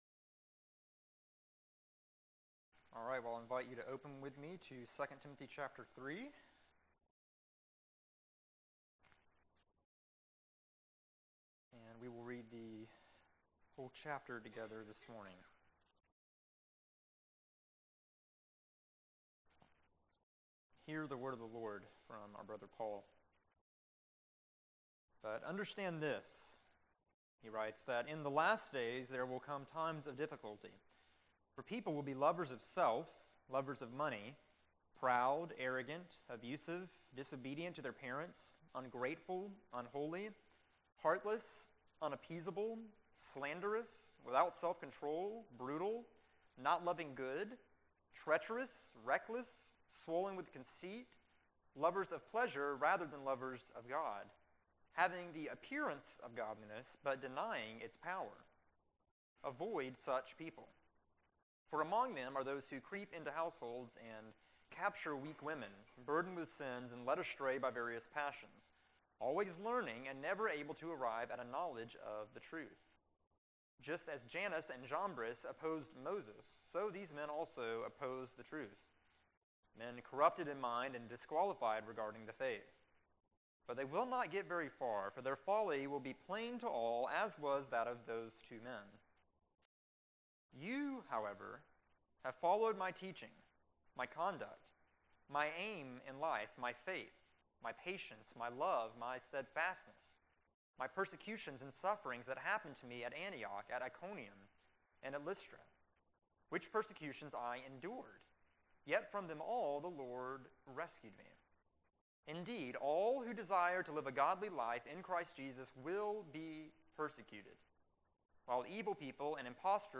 April 24, 2016 Morning Worship | Vine Street Baptist Church
Date Reference Title Speaker April 24, 2016 AM 2 Timothy 3 What is the Greatest Threat?